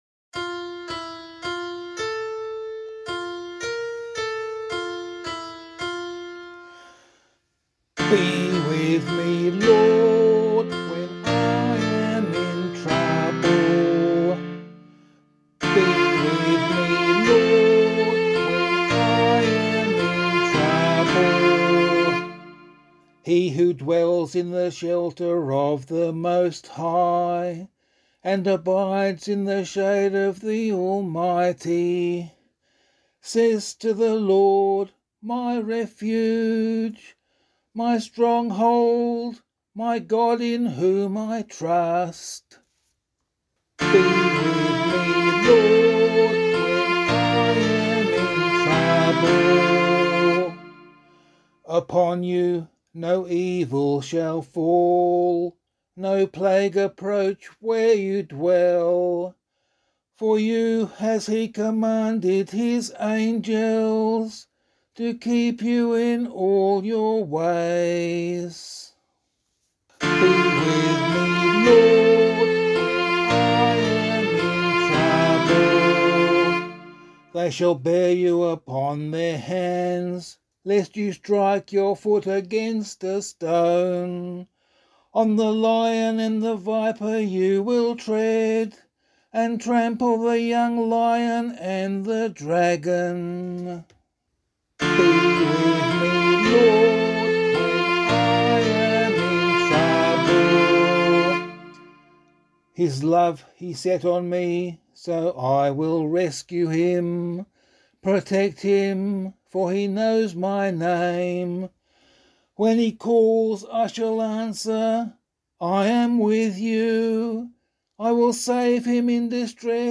013 Lent 1 Psalm C [LiturgyShare 1 - Oz] - vocal.mp3